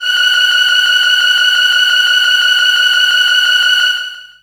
55be-syn21-f#5.aif